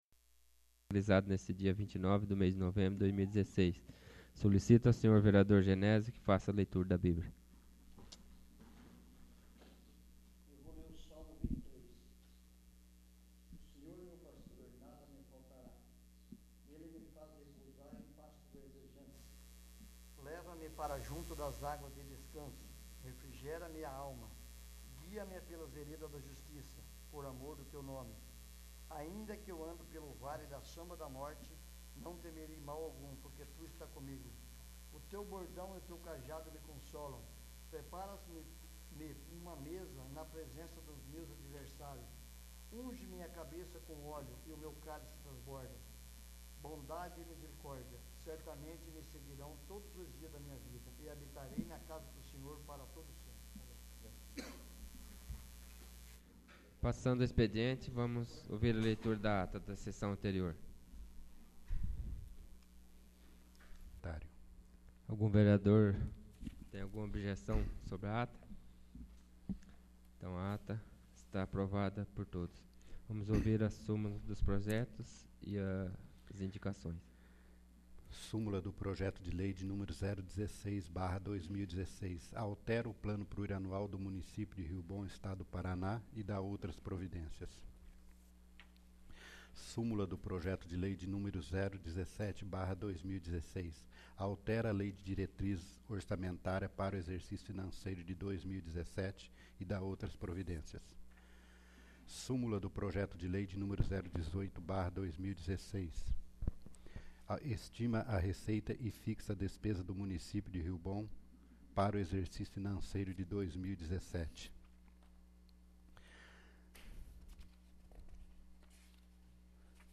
32º. Sessão Ordinária